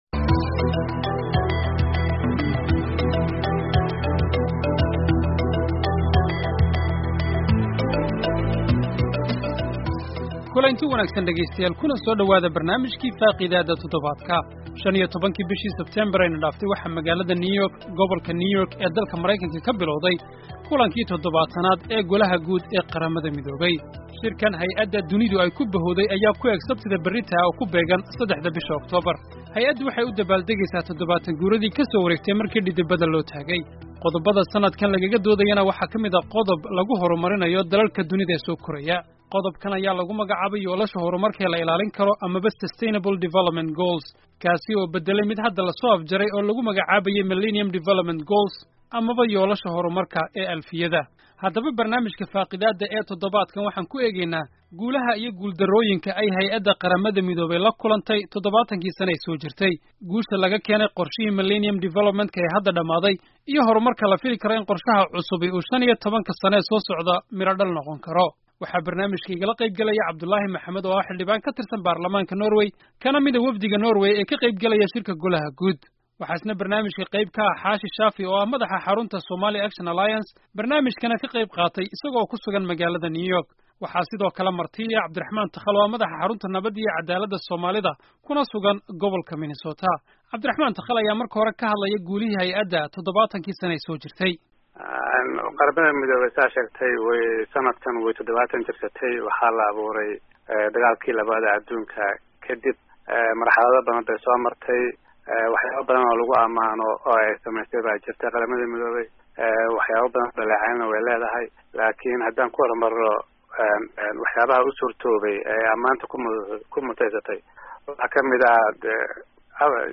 Guulaha iyo guul darrooyinka ay la kulantay hay'ada Qarramada Midoobey toddobaatankii sano ee la soo dhaafay. Barnaamijka waxa ka qeyb galaya aqoonyahanno kala gaddisan oo faaqidaya arrimahan.